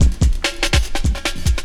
16 LOOP10 -L.wav